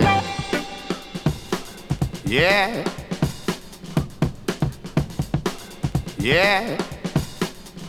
• 122 Bpm Fresh Drum Beat G Key.wav
Free drum loop sample - kick tuned to the G note. Loudest frequency: 1306Hz
122-bpm-fresh-drum-beat-g-key-Ap4.wav